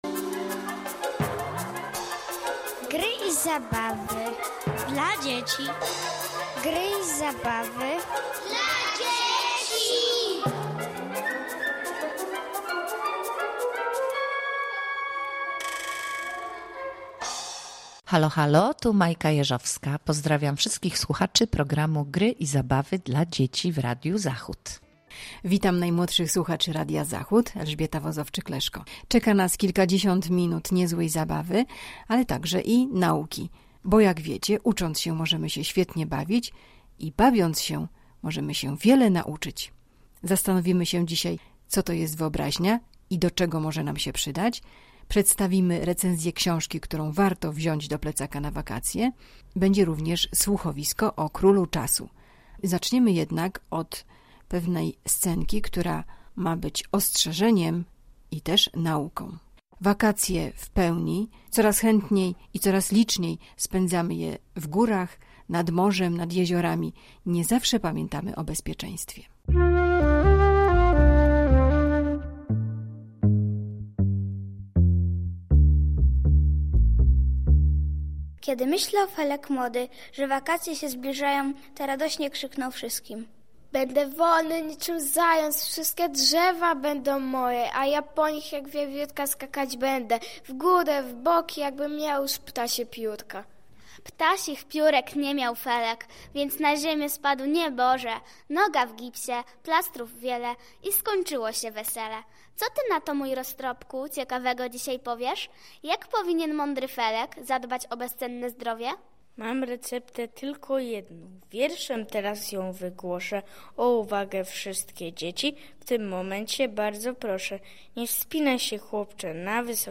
Bezpieczne wakacje – scenka rodzajowa. Co to jest wyobraźnie i do czego nam służy.
Słuchowisko „Król czasu”